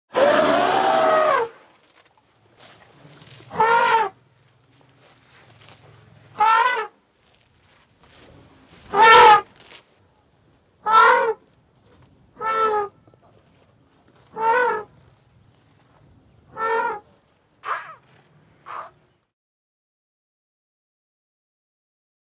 جلوه های صوتی
دانلود صدای فیل 13 از ساعد نیوز با لینک مستقیم و کیفیت بالا